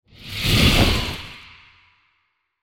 جلوه های صوتی
دانلود صدای آتش 13 از ساعد نیوز با لینک مستقیم و کیفیت بالا